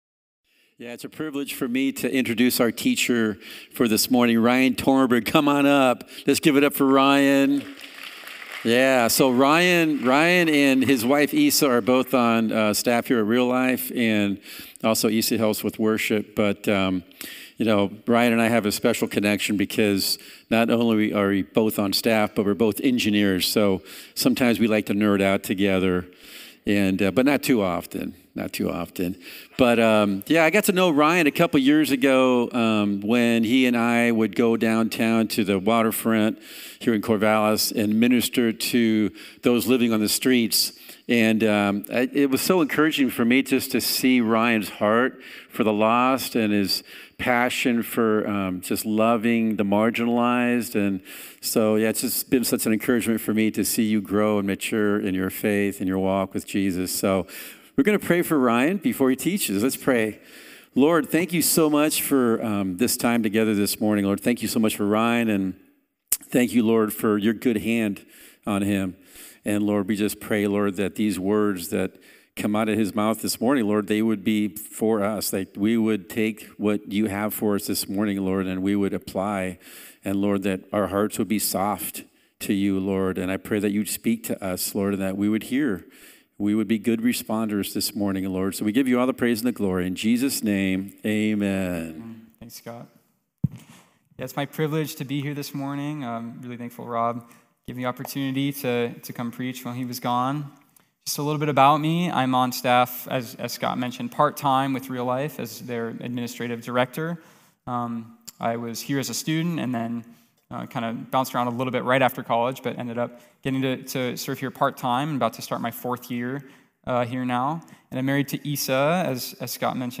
Bible studies given at Calvary Corvallis (Oregon).